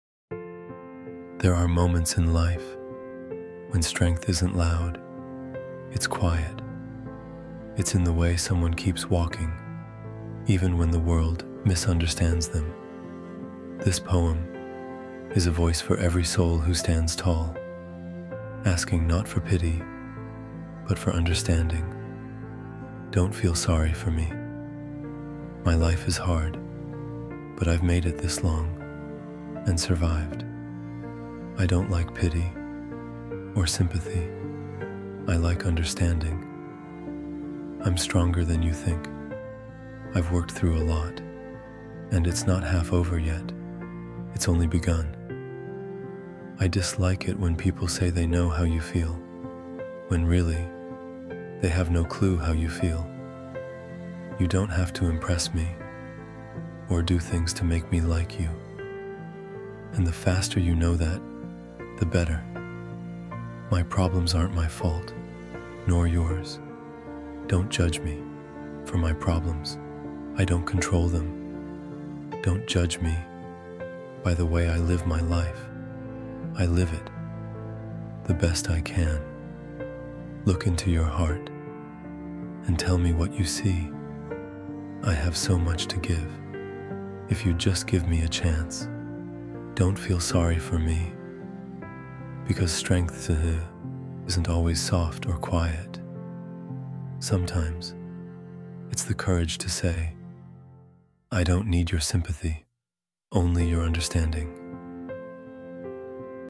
Dont-Feel-Sorry-for-Me-–-Empowering-Spoken-Word-Poem-MP3.mp3